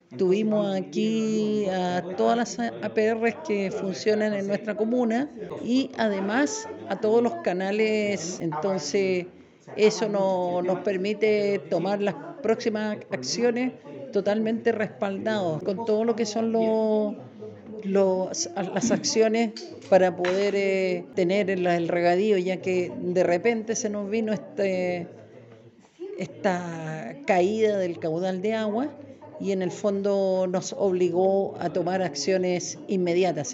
La alcaldesa Verónica Rossat, destacó la excelente convocatoria que tuvo la reunión y el respaldo por parte de los asistentes, a las acciones acordadas de forma inmediata tras la caída del caudal del río.
Alcaldesa-de-Hijuelas.mp3